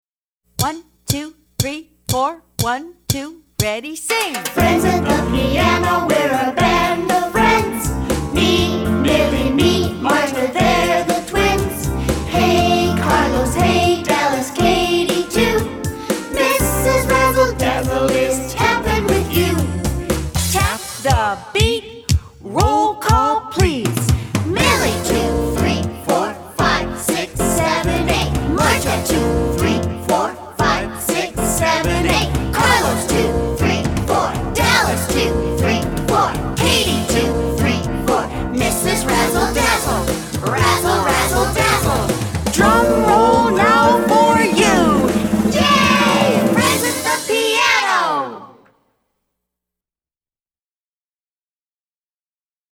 Recueil pour Piano